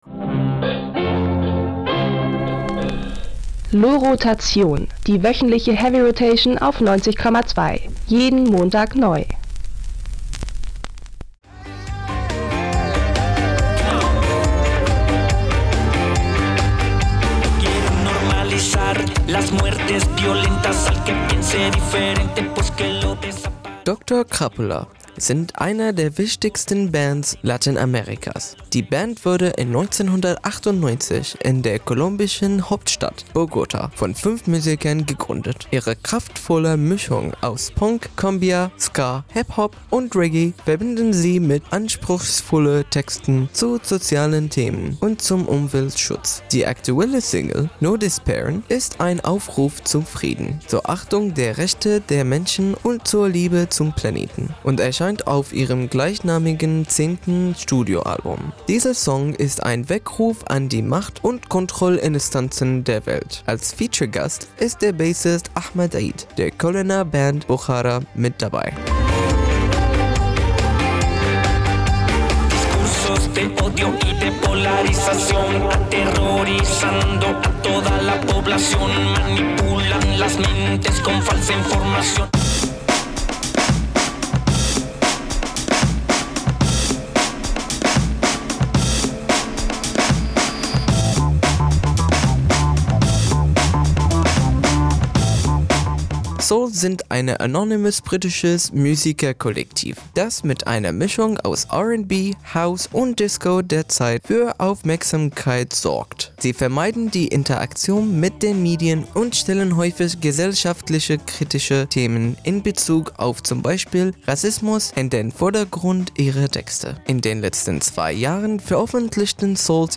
Gitarre
Bass
Drums